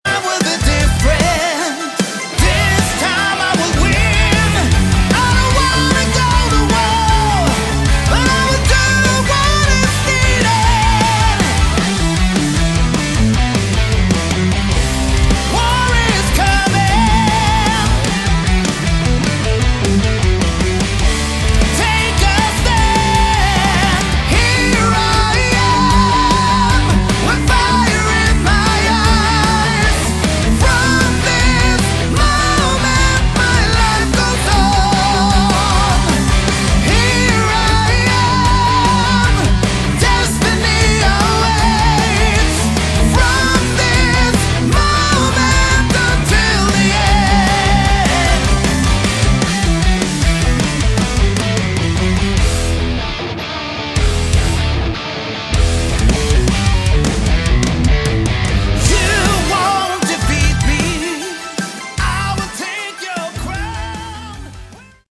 Category: Melodic Hard Rock
vocals
guitars
keyboards
bass
drums